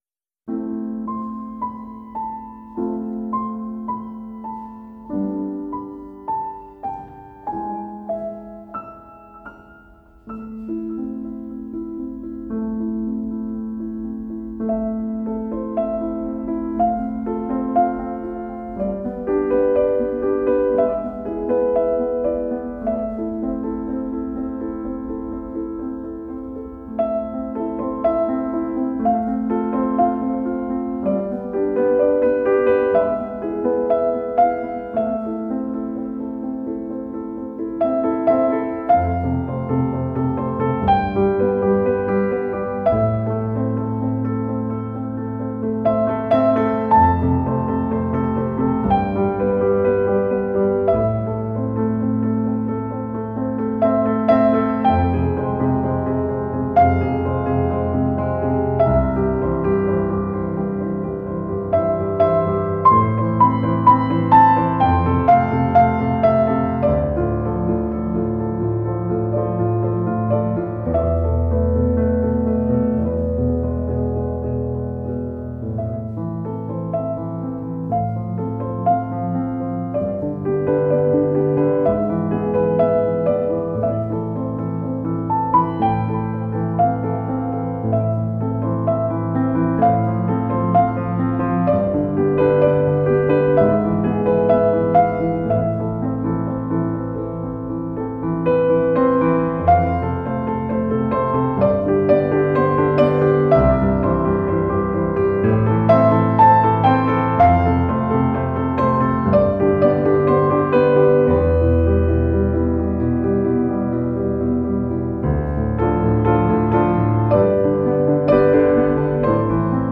Original movie soundtrack